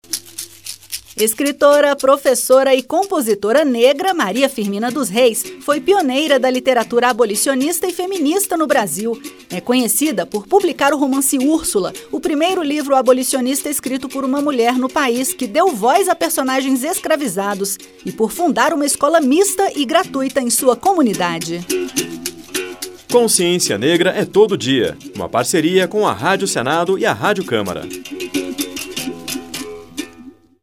A Rádio Senado e a Rádio Câmara lançam uma série de cinco spots que homenageiam personalidades negras que marcaram a história do Brasil, como Abdias Nascimento, Carolina Maria de Jesus, Dragão do Mar, Maria Firmina dos Reis e Luiz Gama.